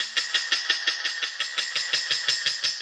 RI_DelayStack_85-02.wav